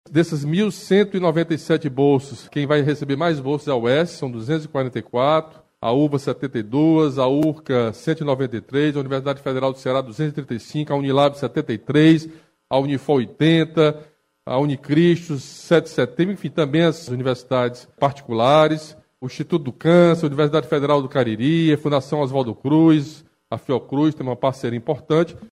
O anúncio foi feito pelo governador do Camilo Santana, na manhã desta quarta-feira (04), durante live no Palácio da Abolição.
O governador Camilo Santana apresentou como ficaram distribuídas as bolsas, por instituição.